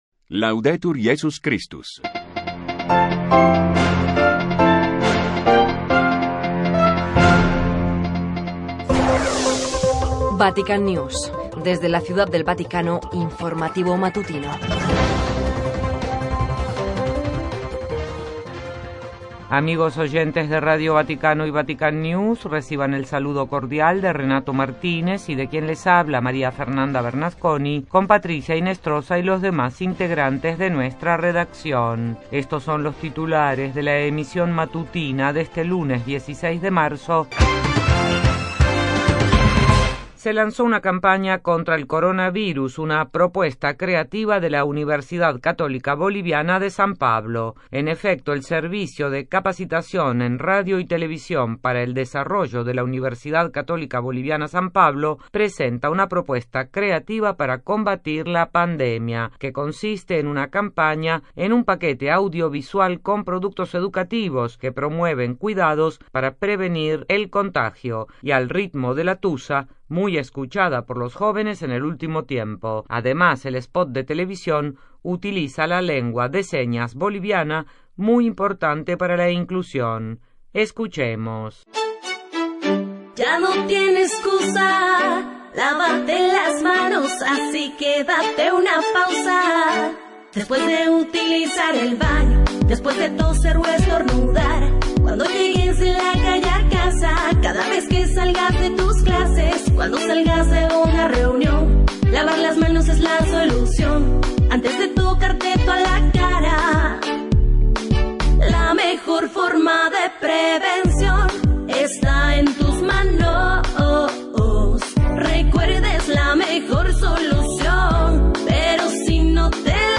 Transmisión radial “noticias del Vaticano” (Vatican News)
RESUMEN-RADIO-VATICANO-16-DE-MARZO.mp3